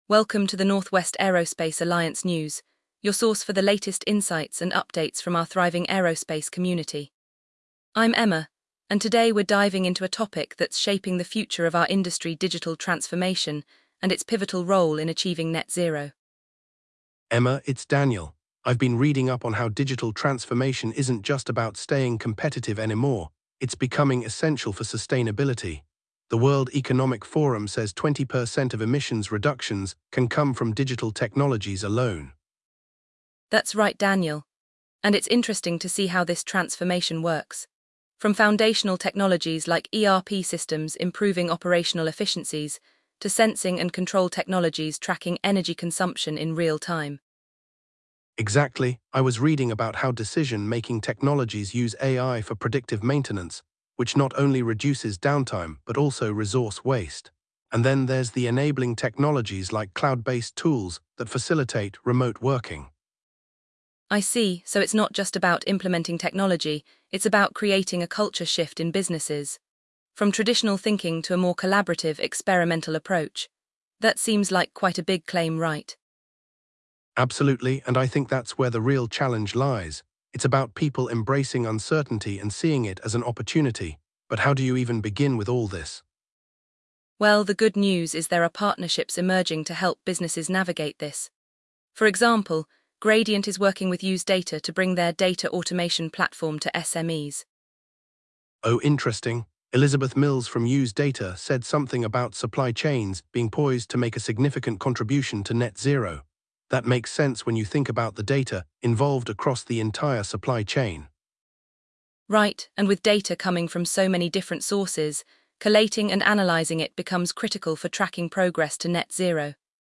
The conversation highlights the cultural shift required to embrace digital change and the partnerships emerging to support businesses, such as Gradient working with YuzeData to bring data automation solutions to SMEs.